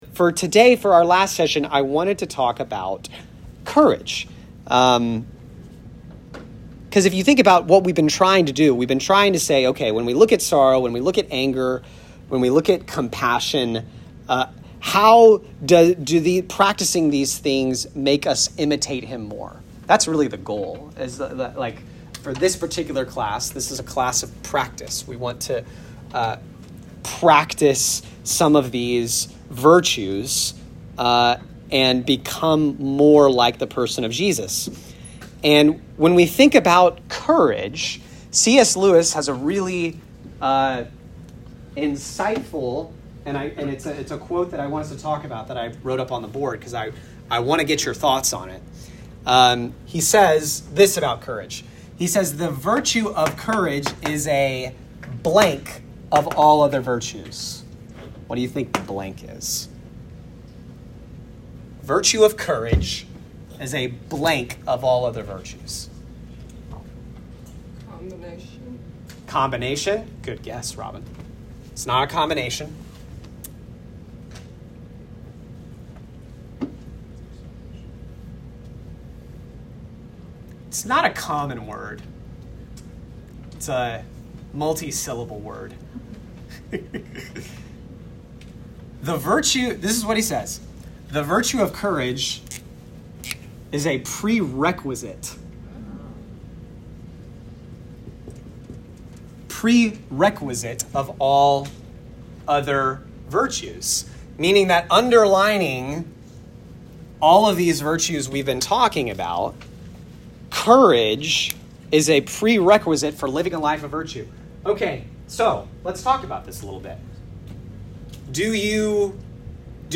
Equip Class: Behold The Man - Lesson 8: Jesus and Courage